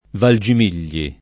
Valgimigli [ val J im & l’l’i ] cogn.